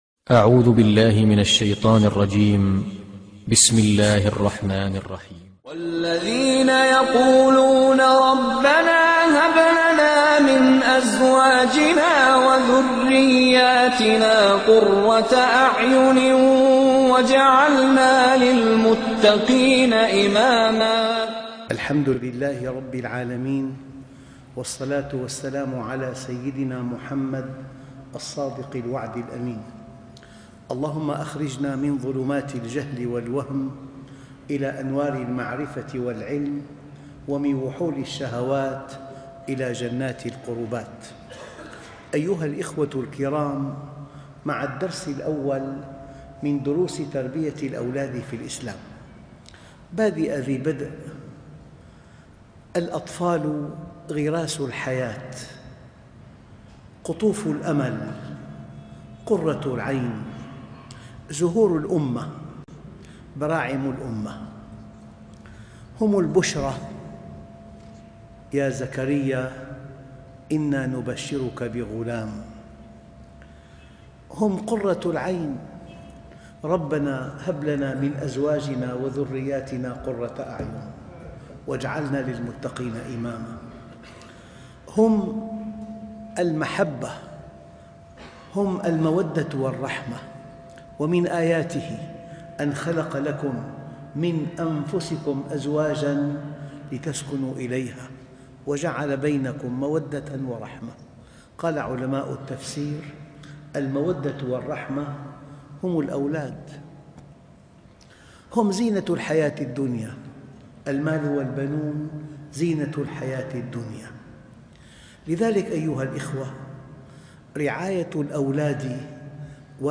( الدرس 1 ) تمهيد _ رعاية الأولاد واجب دينى ( 23/2/2008 ) ( تربية الأولاد فى الإسلام ) - الشيخ محمد راتب النابلسي